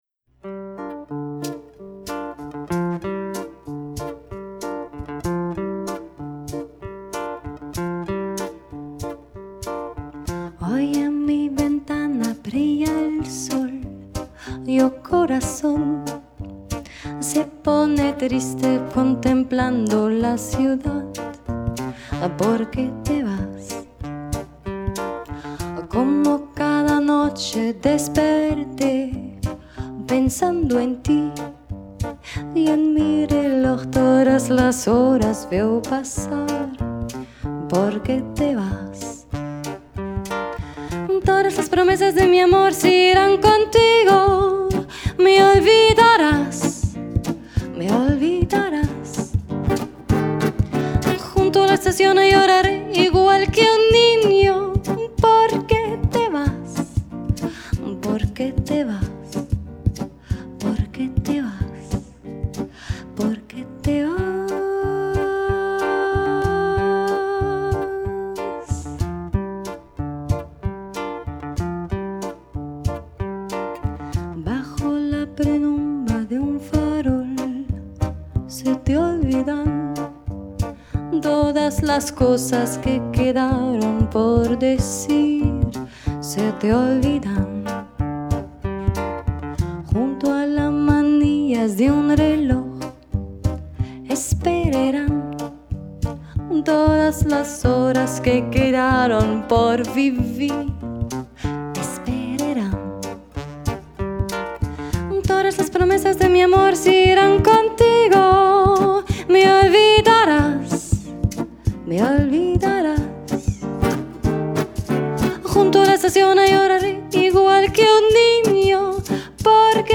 gefühlvolle und ausdrucksstarke Stimme
präzise und klangvolle Gitarre